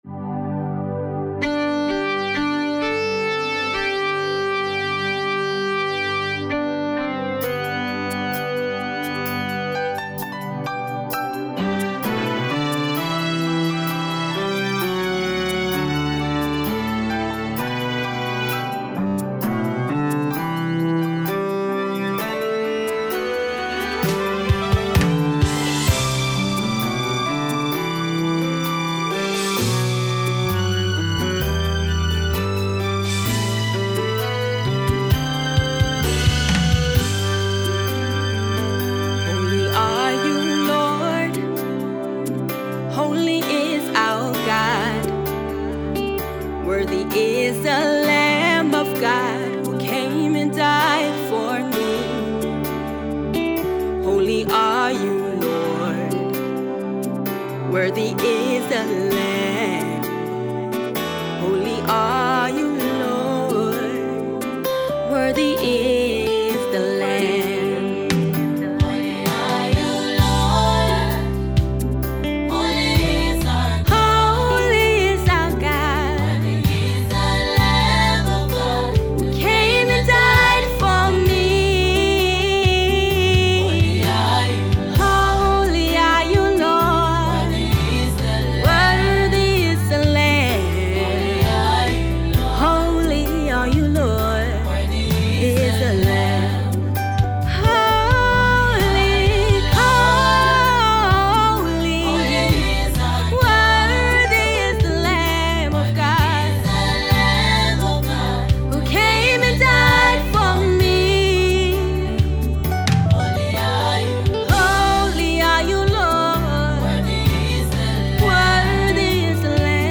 Gospel music
The worship song is purely focused on raising praise to God.